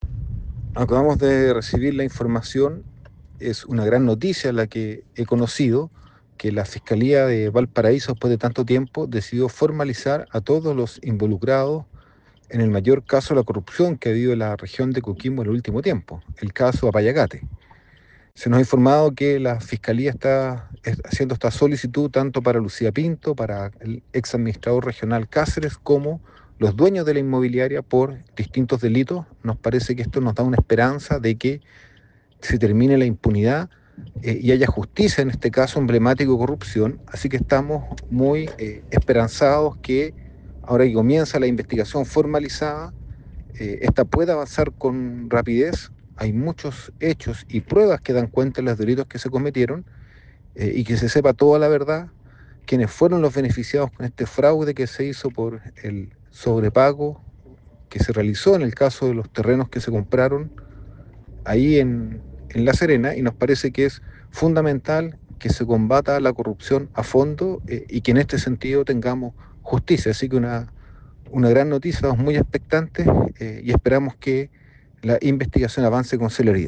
El senador por la región de Coquimbo, Daniel Núñez, querellante en la causa, destacó el avance: